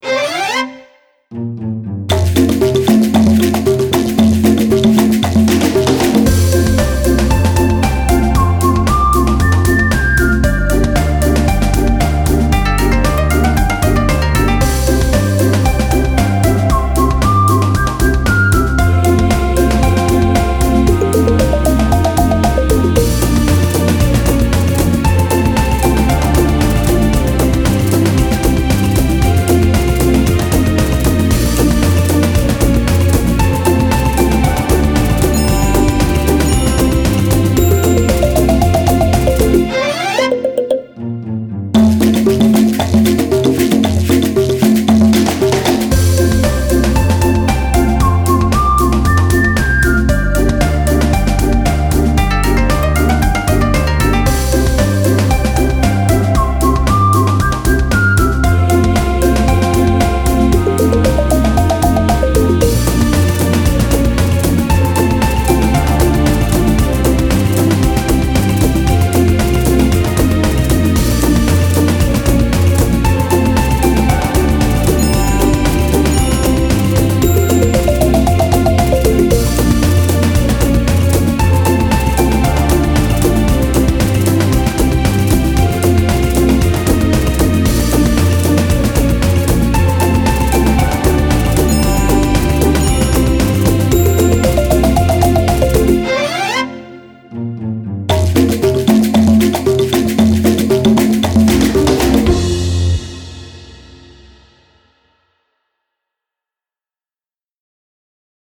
караоке